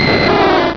pokeemmo / sound / direct_sound_samples / cries / dragonair.wav